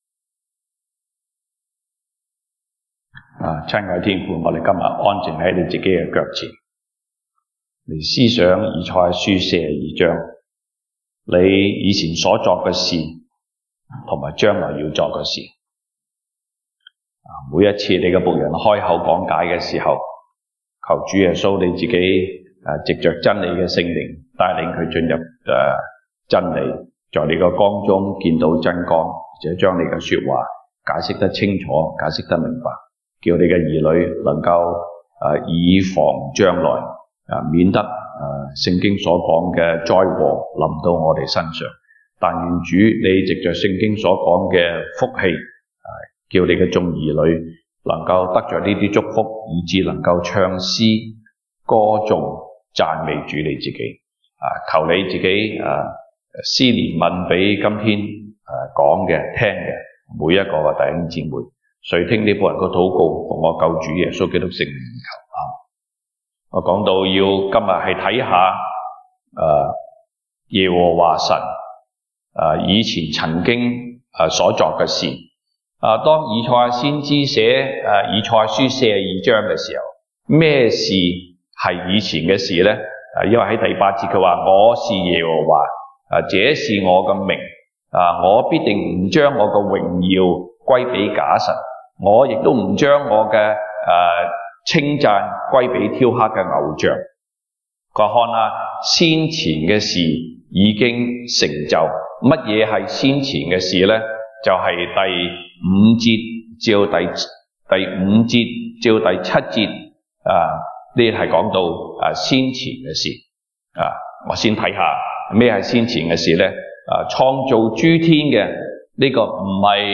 東北堂證道 (粵語) North Side: 甚麼是主的僕人已經和將來要作的大事？